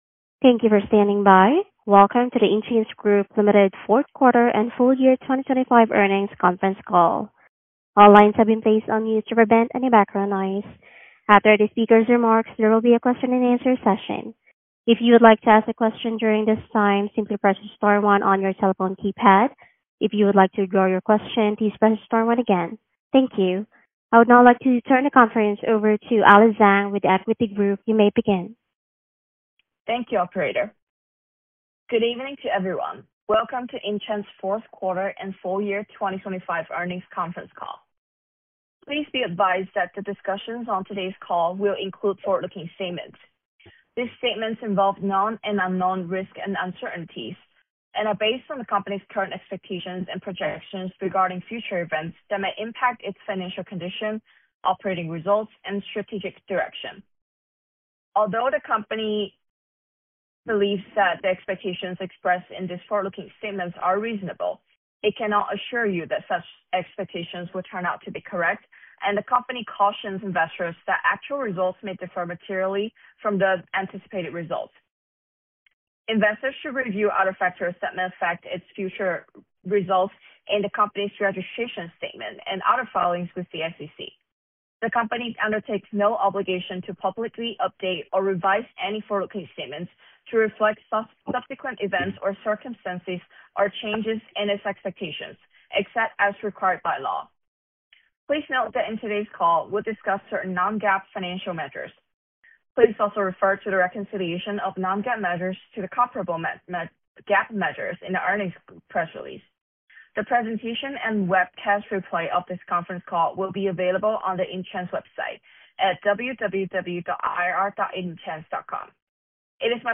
Intchains-Group-Limited-Fourth-Quarter-and-Full-Year-2025-Earnings-Conference-Call.mp3